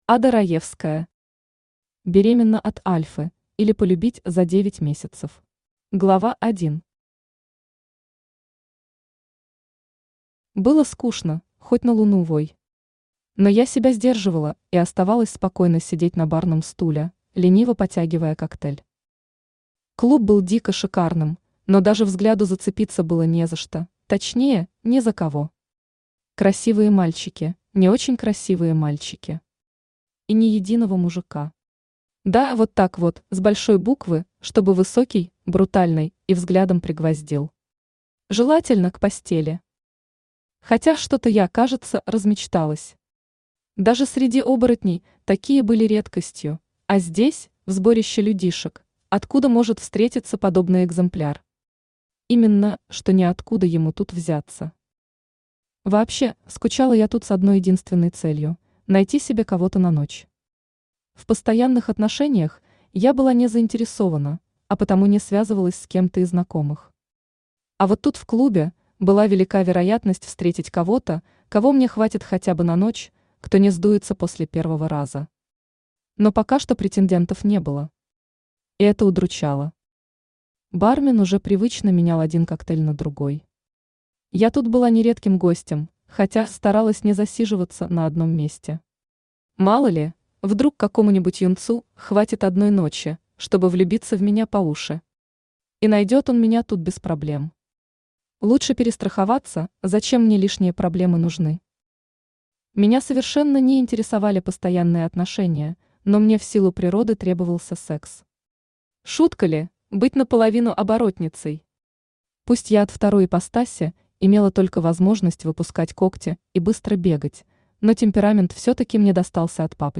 Aудиокнига Беременна от альфы, или Полюбить за 9 месяцев Автор Ада Раевская Читает аудиокнигу Авточтец ЛитРес.